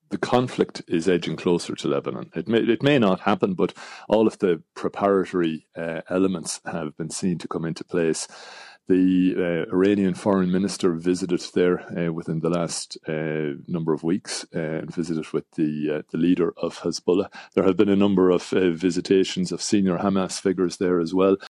Defence and Security expert